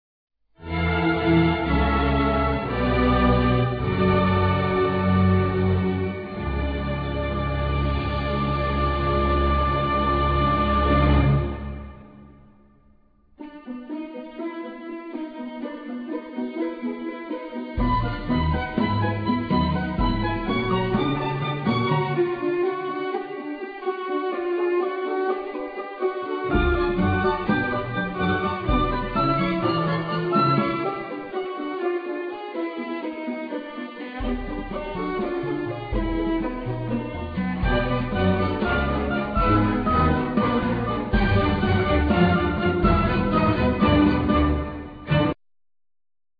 Classical&12 string guitar,Piano,synths
Oboe,Soprano sax,Bass Clarinet
Acoustic bass
Drums,Percussion